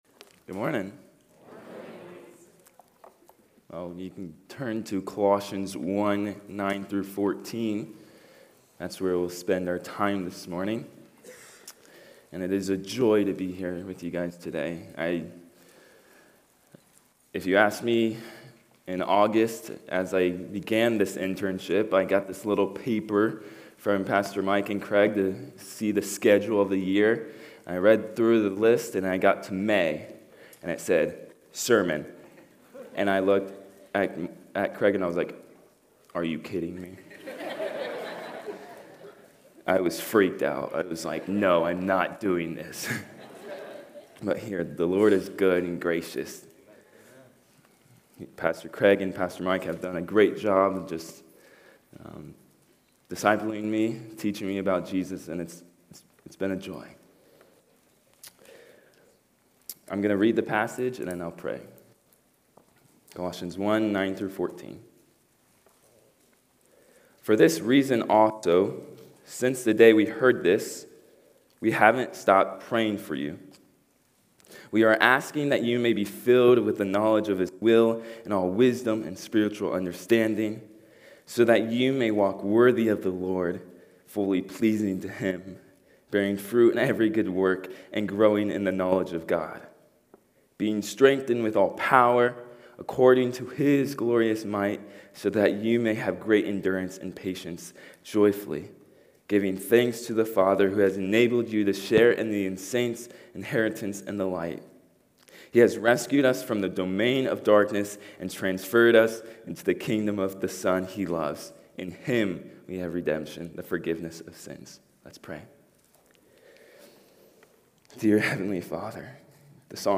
4-27-25-Sunday-Service.mp3